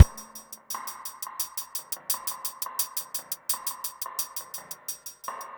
Index of /90_sSampleCDs/Best Service ProSamples vol.40 - Breakbeat 2 [AKAI] 1CD/Partition B/MEANGREEN086